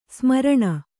♪ smaraṇa